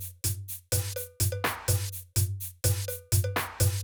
Drumloop 125bpm 08-B.wav